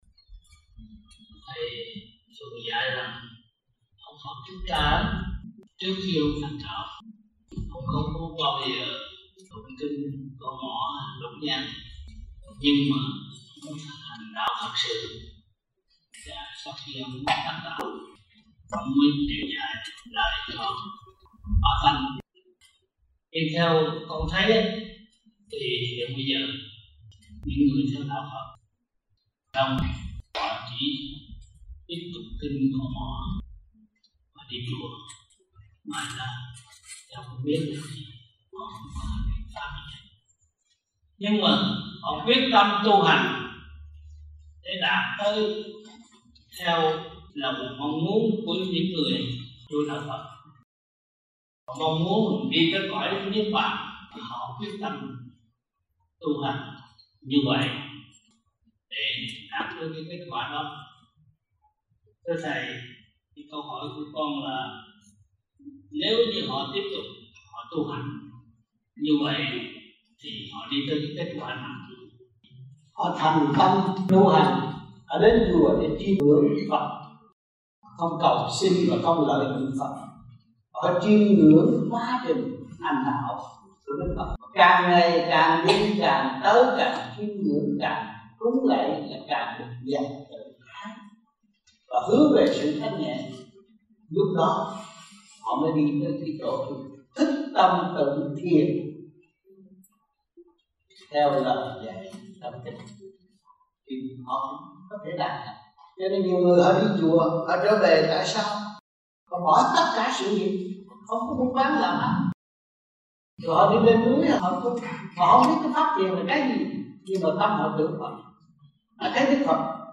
Băng Giảng Và Vấn Đạo Tại Những Đại Hội Vô Vi Quốc Tế